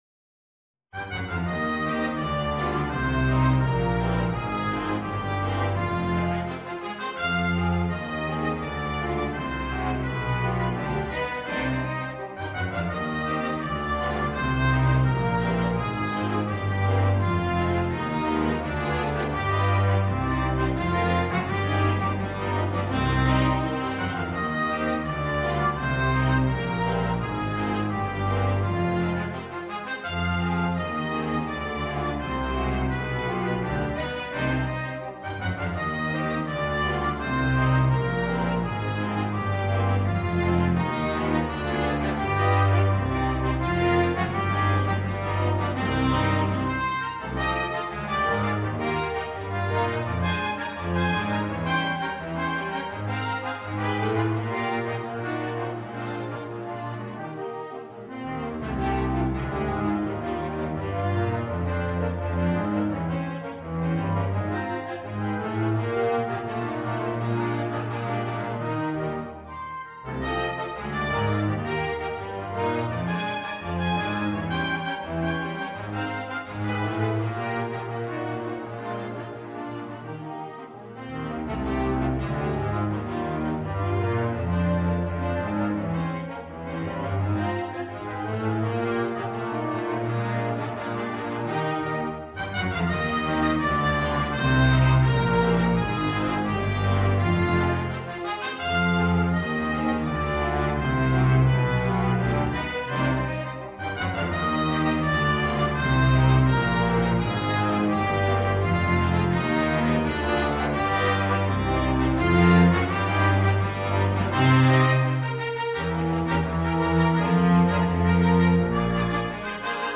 Free Sheet music for Flexible Mixed Ensemble - 5 Players
ClarinetFluteTrumpetViolinGuitar (Chords)
Double BassTromboneTubaCello
Eb major (Sounding Pitch) (View more Eb major Music for Flexible Mixed Ensemble - 5 Players )
2/4 (View more 2/4 Music)
World (View more World Flexible Mixed Ensemble - 5 Players Music)